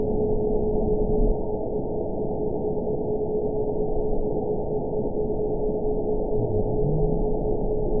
event 922324 date 12/29/24 time 23:37:58 GMT (4 months ago) score 8.81 location TSS-AB04 detected by nrw target species NRW annotations +NRW Spectrogram: Frequency (kHz) vs. Time (s) audio not available .wav